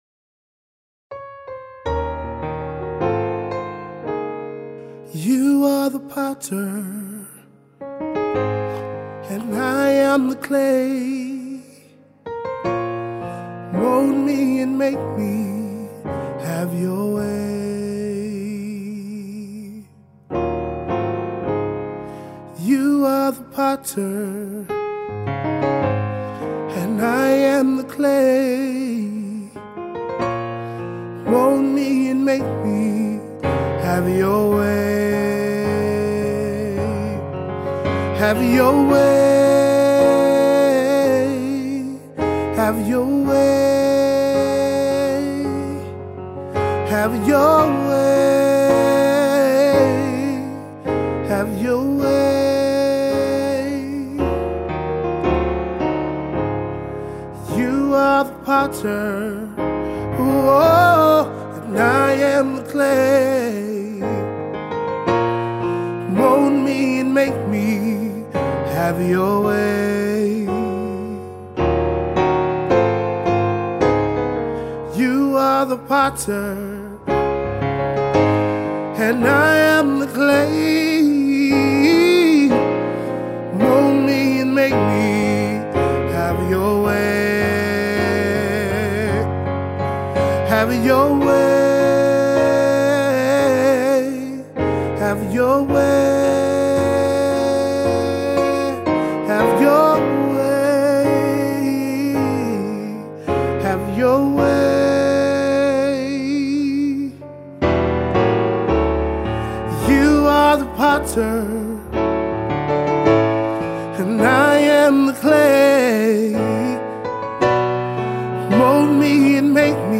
Sermons | James Bay United Church